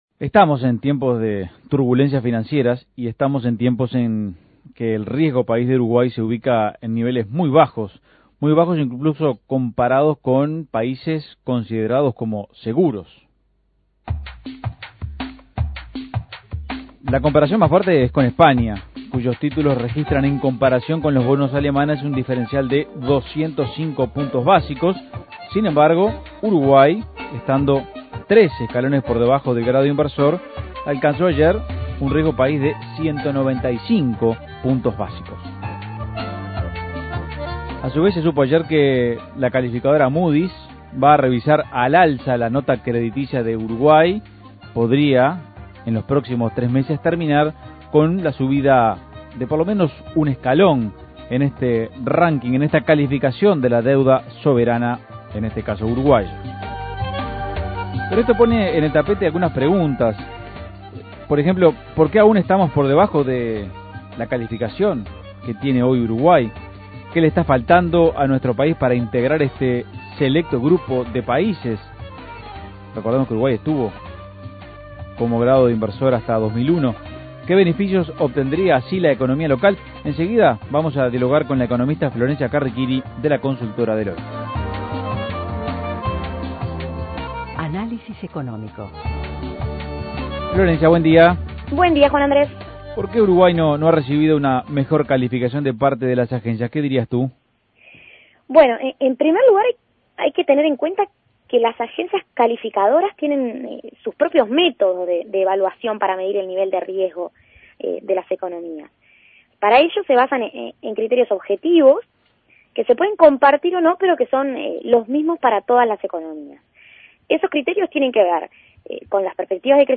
Análisis Económico Uruguay todavía está a tres escalones del grado inversor.